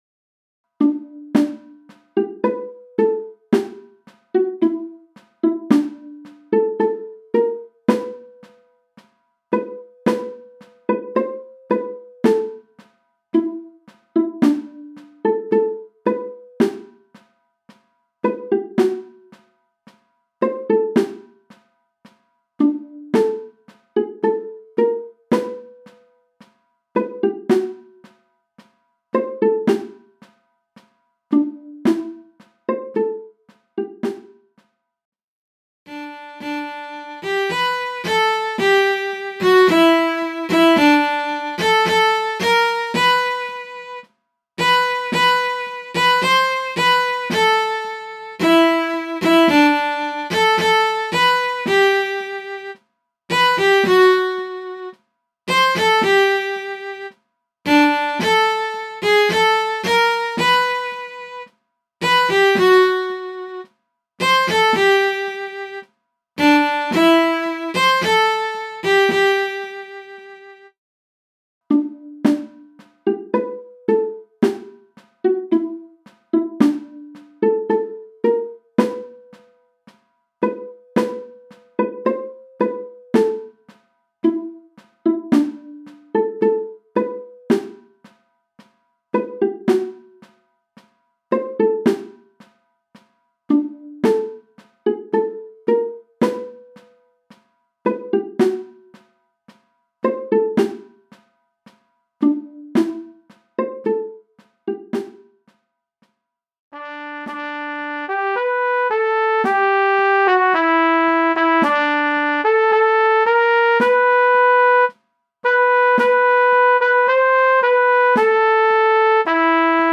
Ballade (2012/Scheffel)
MIDI von 2012 4/4 Takt [7.062 KB] (2012) - mp3